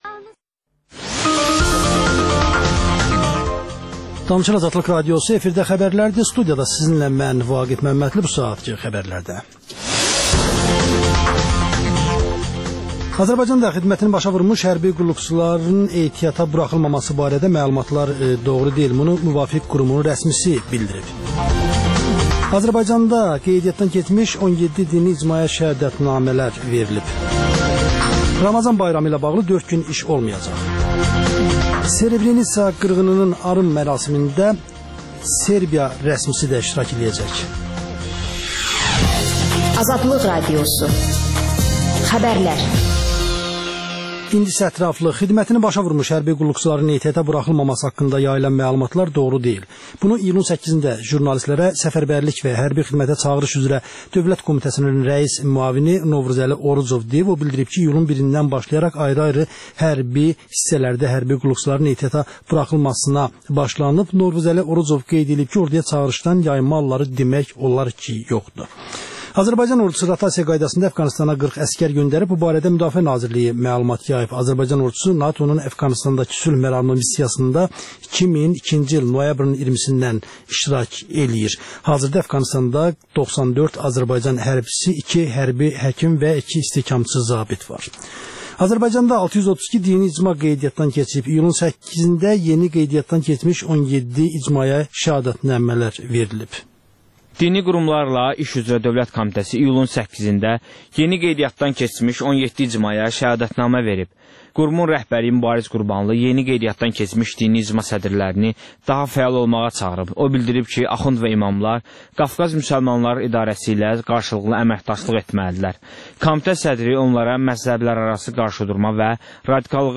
Azərbaycanda və dünyada baş verən ən son yeniliklər barədə operativ xəbər proqramı.